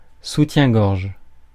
Ääntäminen
IPA : /ˈbræzɪɛə/ IPA : /ˈbræsɪɛə/